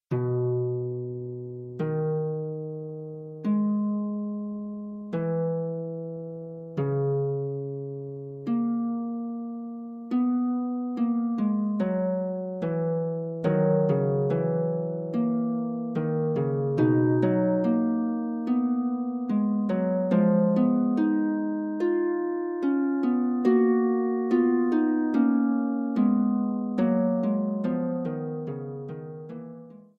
for solo pedal harp.